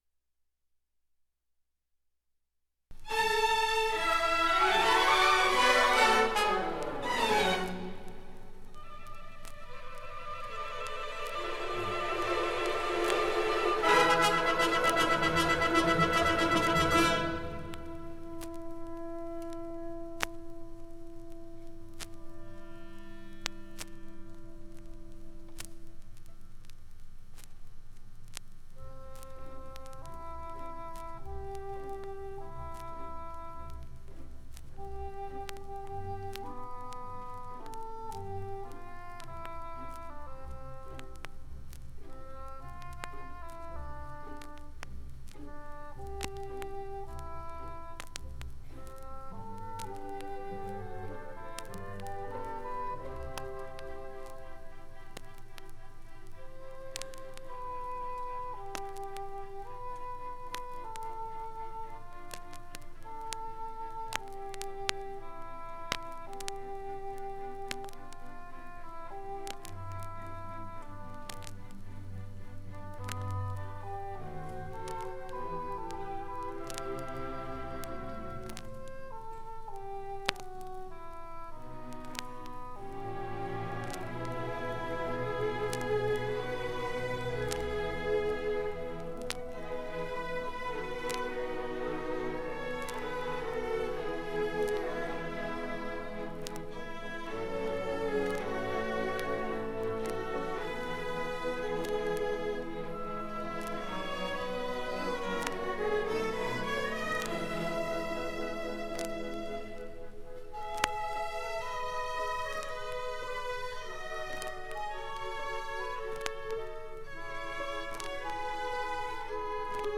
769b536c41510fdfc56e6c8f39c7065fc9d8e138.mp3 Title 1978 Music in May orchestra and chorus performance recording Description An audio recording of the 1978 Music in May orchestra and chorus performance at Pacific University.
It brings outstanding high school music students together on the university campus for several days of lessons and events, culminating in the final concert that this recording preserves.